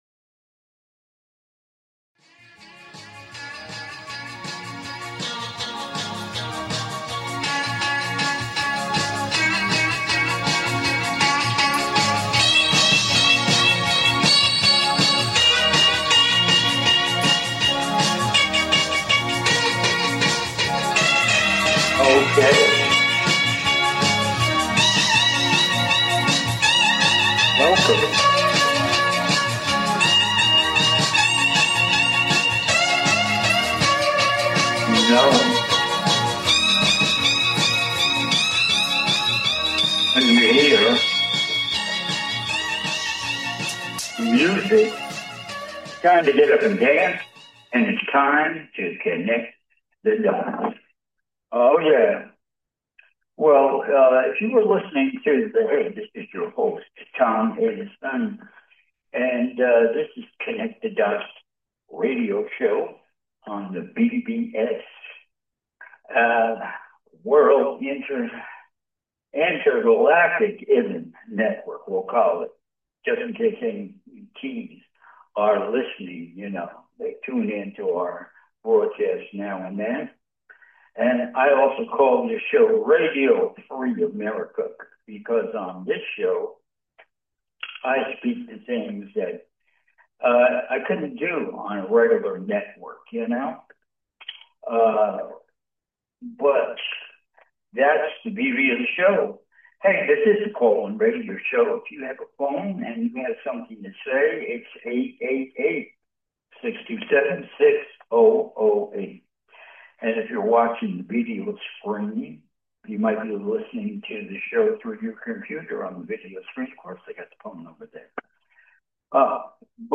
Talk Show Episode, Audio Podcast, Connect The Dots and Spiritual Evolution and the Liberation of Earth on , show guests , about Spiritual Evolution,the Liberation of Earth,Galactic Confederation,spiritual liberation,transition,5th-dimensional reality,Bible,False God,Universe, categorized as Comedy,Entertainment,Paranormal,UFOs,Physics & Metaphysics,Politics & Government,Society and Culture,Theory & Conspiracy